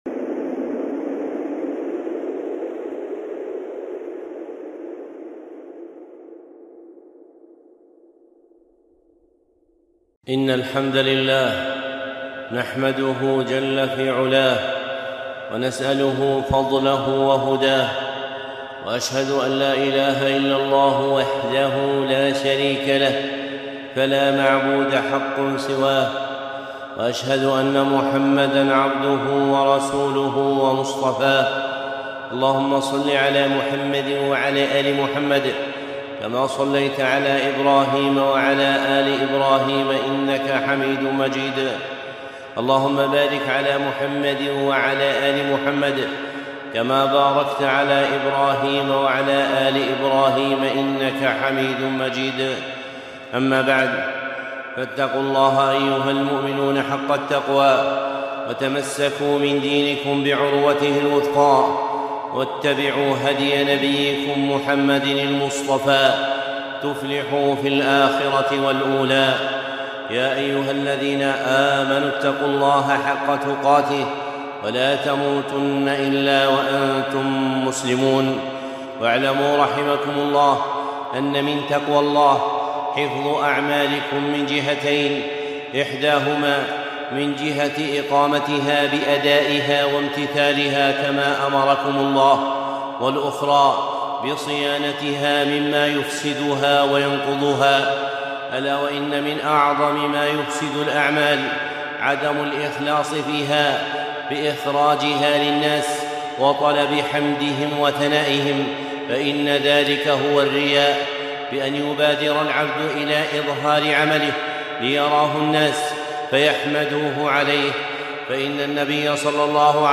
خطبة (الترهيب من الرياء) الشيخ صالح العصيمي